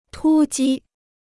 突击 (tū jī): sudden and violent attack; assault.